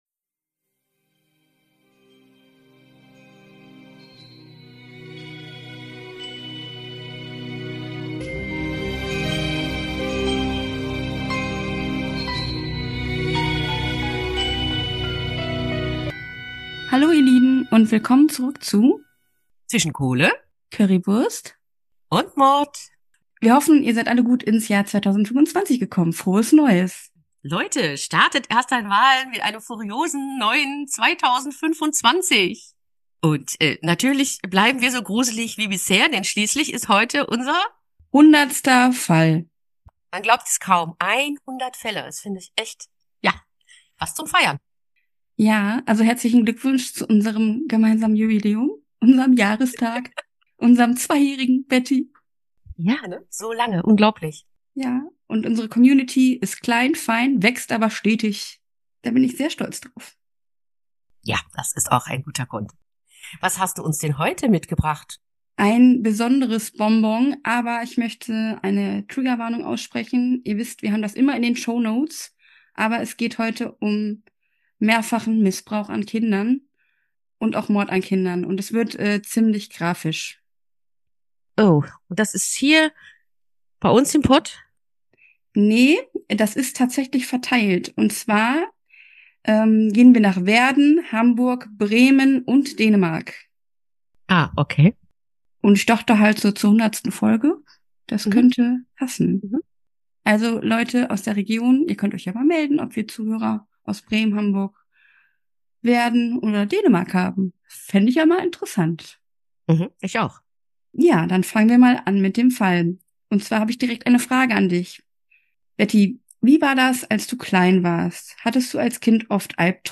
Die jeweils andere stellt Fragen und kommentiert.